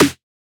Index of /99Sounds Music Loops/Drum Oneshots/Twilight - Dance Drum Kit/Snares